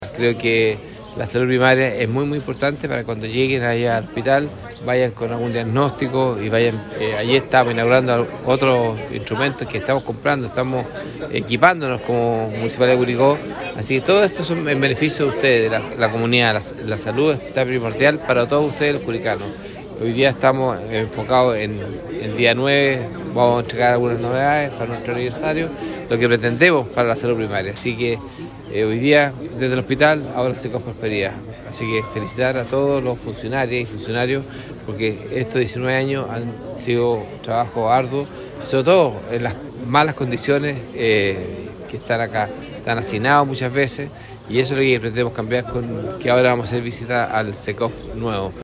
Con una emotiva ceremonia que reunió a autoridades, profesionales de la salud y vecinos del sector poniente de Curicó, el Centro Comunitario de Salud Familiar (Cecosf) Prosperidad conmemoró un nuevo aniversario, reafirmando su rol como referente en la atención primaria.